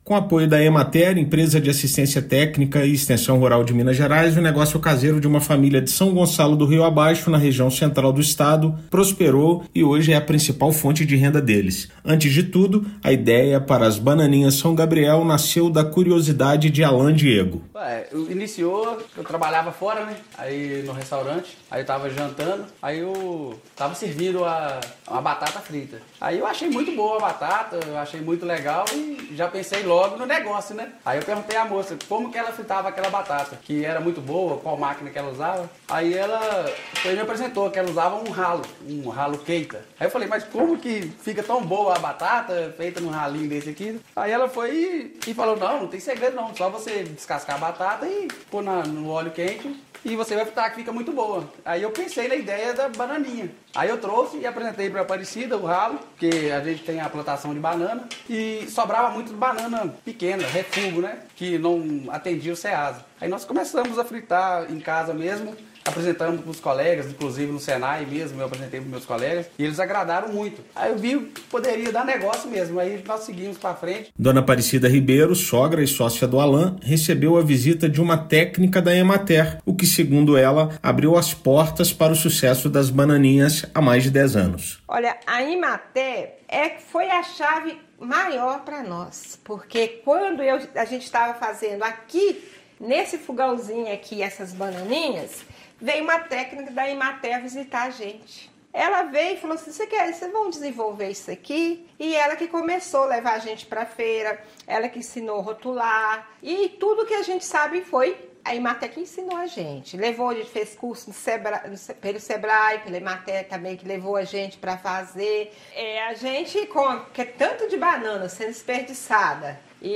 [RÁDIO] Com assistência técnica garantida, agroindústria gera emprego e renda em São Gonçalo do Rio Abaixo
Apoio do Governo de Minas, por meio da Empresa de Assistência Técnica e Extensão Rural do Estado de Minas Gerais (Emater–MG), foi fundamental para o crescimento do negócio, que começou no fogão a lenha e hoje tem toda a estrutura adequada e legalizada para produção de banana chips. Ouça matéria de rádio.